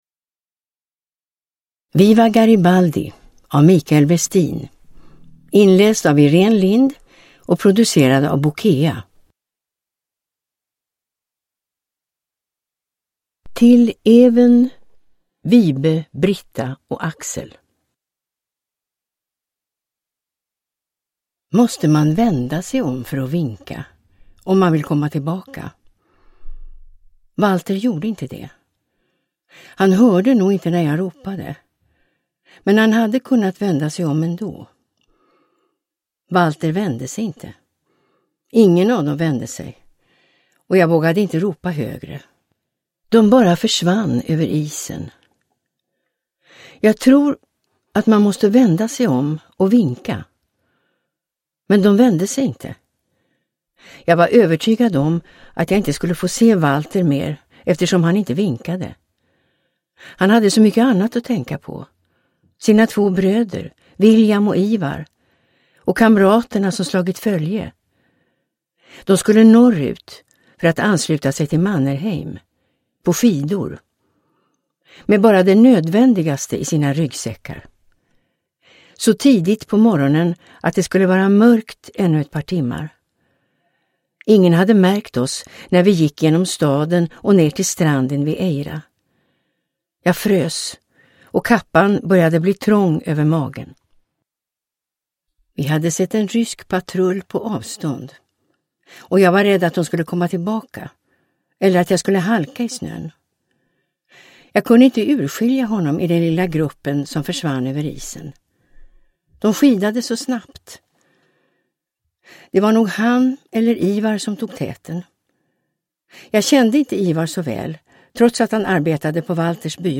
Uppläsare: Irene Lindh
Ljudbok